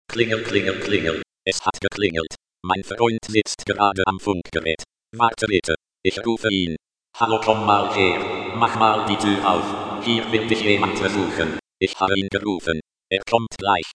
Roboterstimme
Sanders Elektroniklabor - Wecker und Türklingel mit Roboterstimme
klingel1.mp3